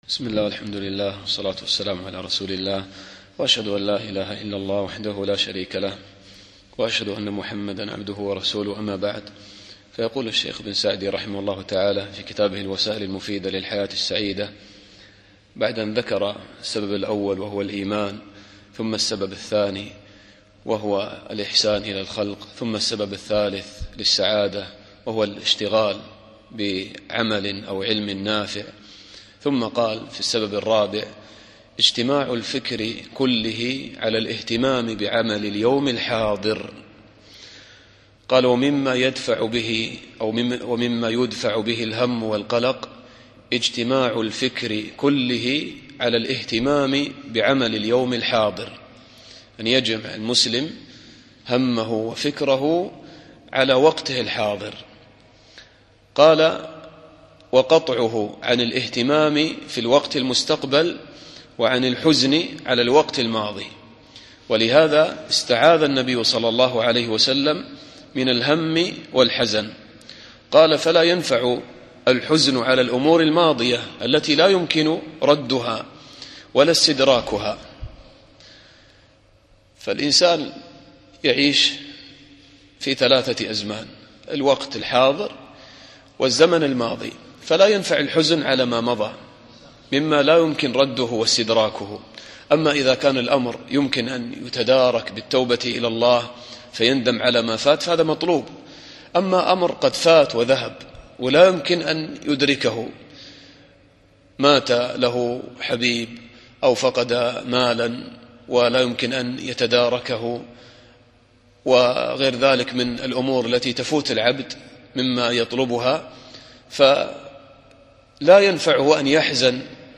الدرس السادس